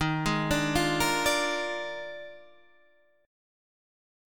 D#M7sus2sus4 chord